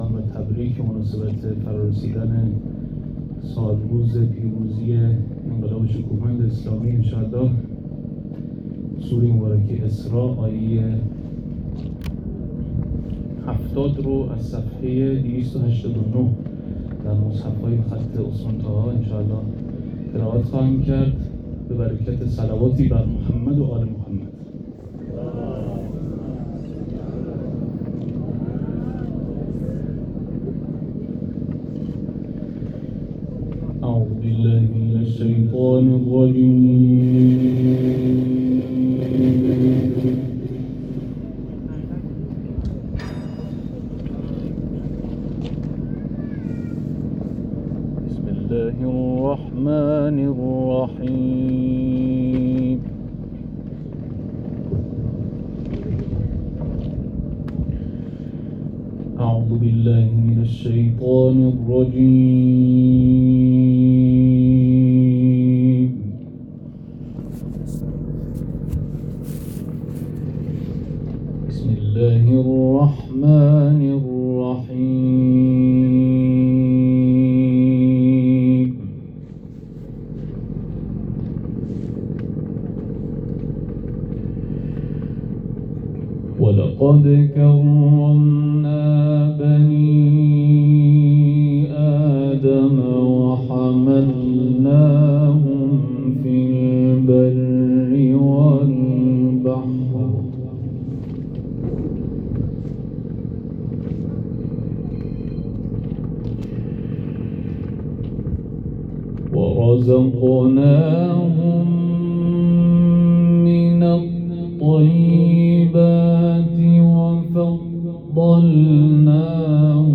تلاوت
در چهارمین محفل قرآنی انقلاب